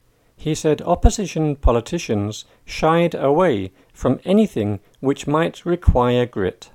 DICTATION 8